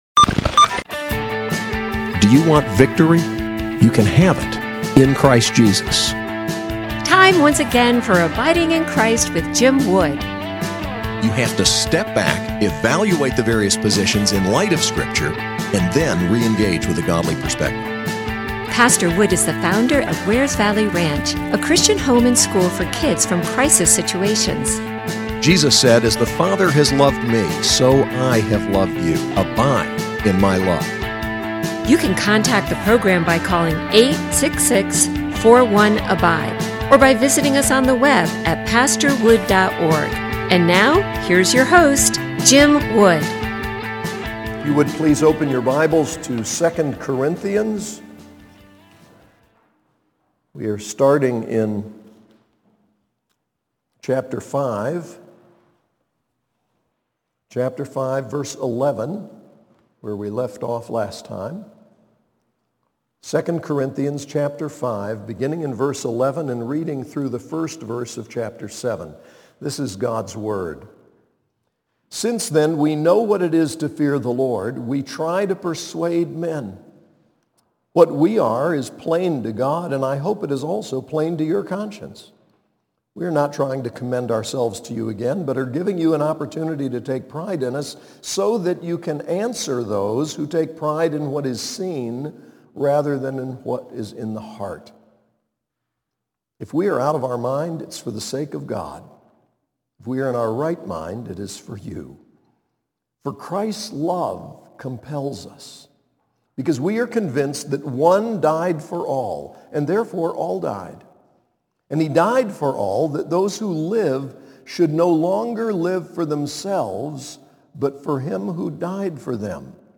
SAS Chapel: 2 Corinthians 5:11-7:1